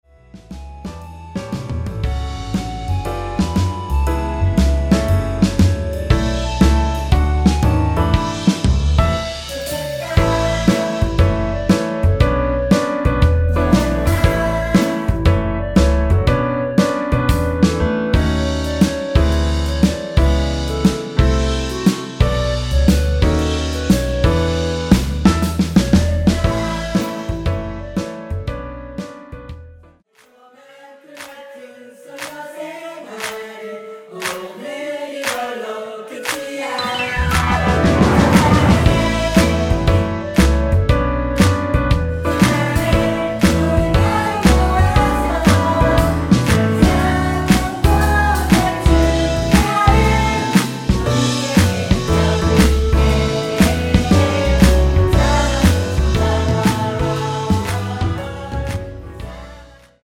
원키에서(+2)올린 멜로디와 코러스 포함된 MR입니다.(미리듣기 참조)
앞부분30초, 뒷부분30초씩 편집해서 올려 드리고 있습니다.
중간에 음이 끈어지고 다시 나오는 이유는
(멜로디 MR)은 가이드 멜로디가 포함된 MR 입니다.